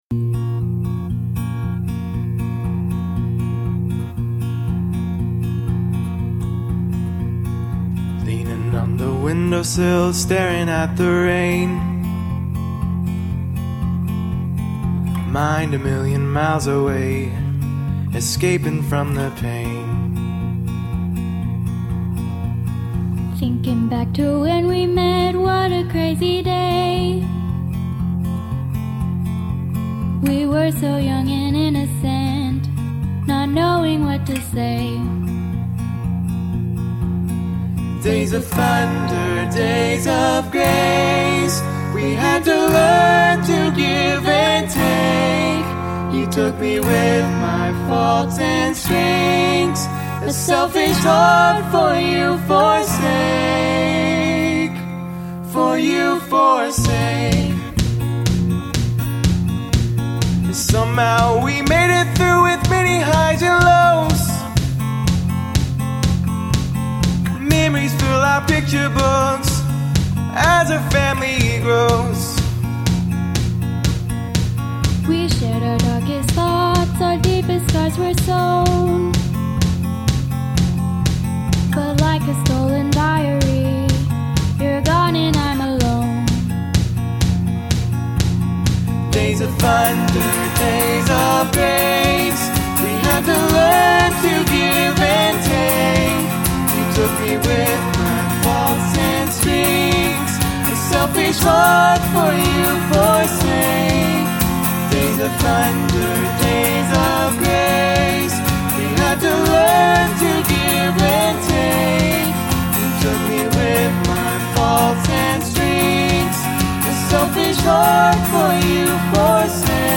Vocal Harmony